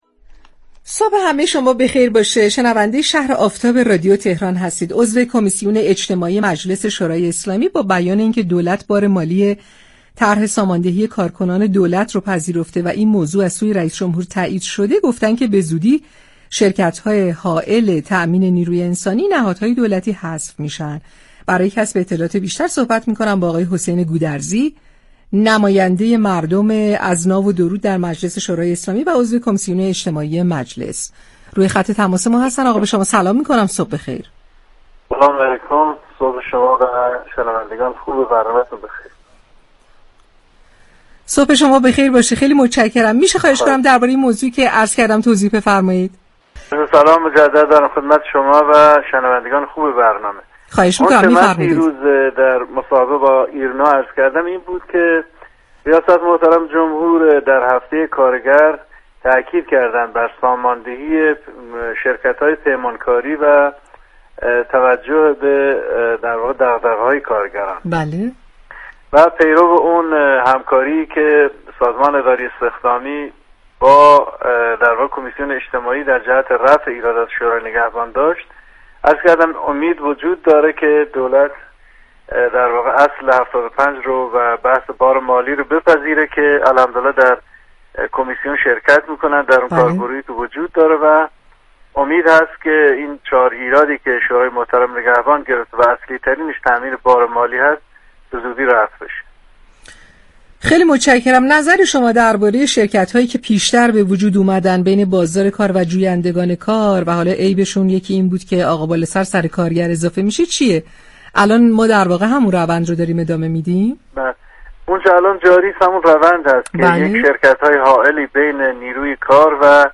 به گزارش پایگاه اطلاع رسانی رادیو تهران، عباس گودرزی عضو كمیسیون اجتماعی مجلس شورای اسلامی در گفت و گو با «شهر آفتاب» در مورد طرح ساماندهی كاركنان دولت اظهار داشت: رئیس جمهور در هفته كارگر بر ساماندهی شركت‌های پیمانكاری تاكید كردند.